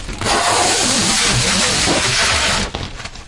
描述：展开重型胶带。
Tag: 建设性 音频 重型磁带 掩蔽 胶带 HIFI 管道胶带 胶带 大气 纸箱 气氛